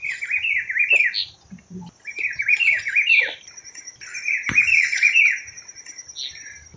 Rufous-browed Peppershrike (Cyclarhis gujanensis)
Life Stage: Adult
Location or protected area: Parque Natural Municipal Ribera Norte (San Isidro)
Condition: Wild
Certainty: Recorded vocal